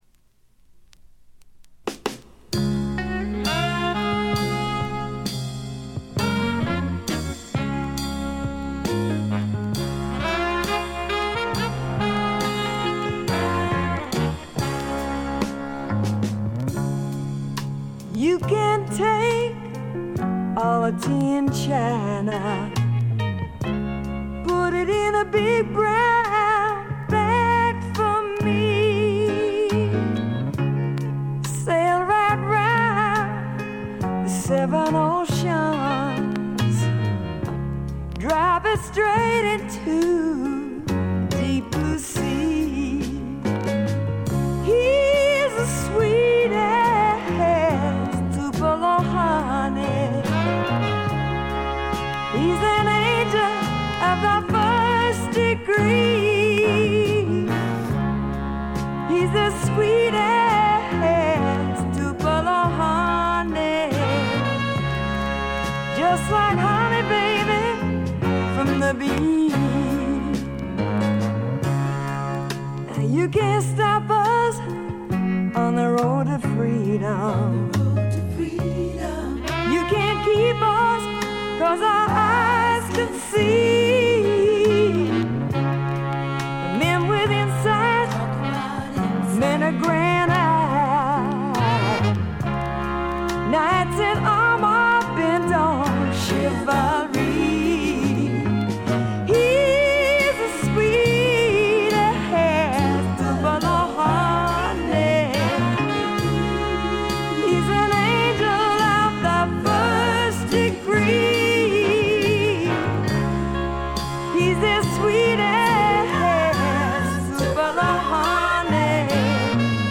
大きなノイズはありません。
ほんと歌の上手い人ですね。スワンプバラードの大傑作です。
試聴曲は現品からの取り込み音源です。